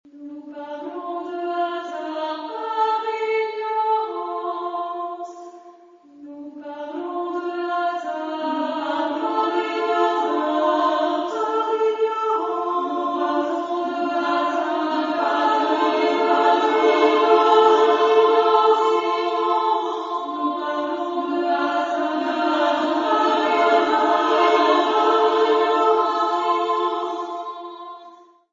Genre-Style-Forme : Profane ; Poème ; contemporain
Type de choeur : SSAA  (4 voix égales OU égales de femmes )
Tonalité : ré mineur